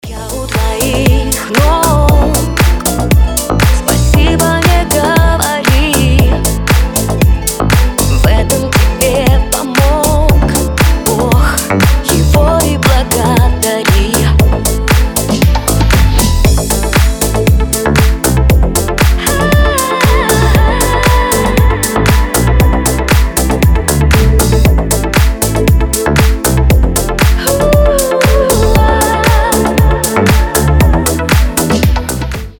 • Качество: 320, Stereo
deep house
душевные
Cover